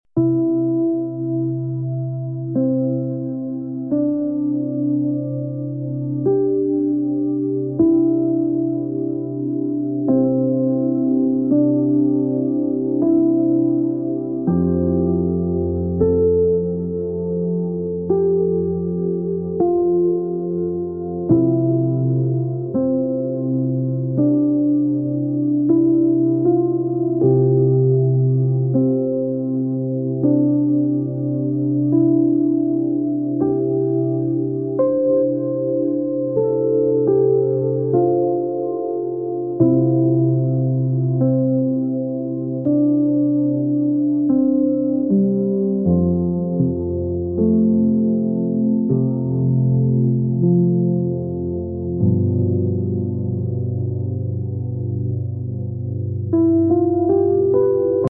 Crafted to enhance your focus, this playlist features soft ambient sounds, mellow piano chords, and subtle background chatter, simulating the tranquil atmosphere of a library. Ideal for students, academics, or anyone needing to concentrate on work or studies. Whether prepping for exams or engaging in deep thought, these Lo-fi beats are designed to help you stay focused and efficient. Subscribe for music that not only calms but also boosts your learning efficiency.